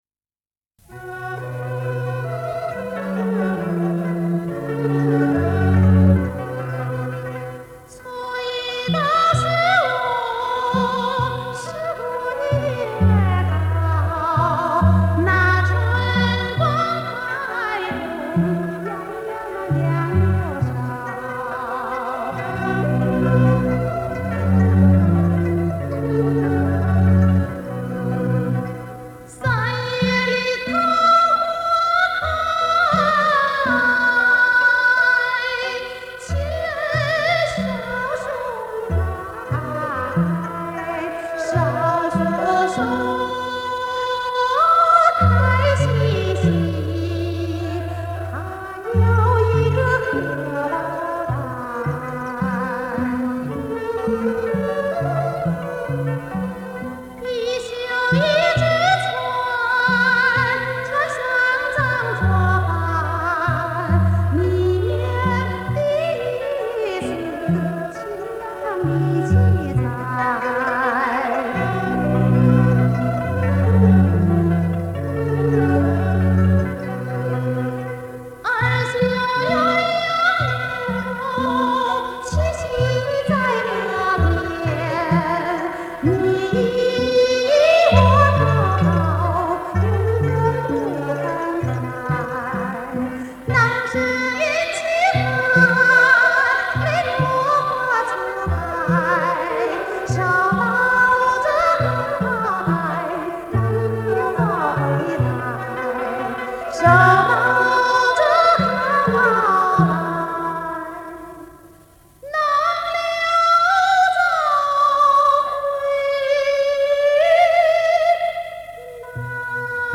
[18/1/2019]著名女高音歌唱家王玉珍演唱的山西民歌《绣荷包》 激动社区，陪你一起慢慢变老！